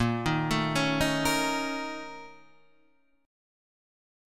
A#mM7bb5 chord {6 6 7 6 4 6} chord